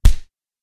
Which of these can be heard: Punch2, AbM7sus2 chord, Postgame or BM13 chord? Punch2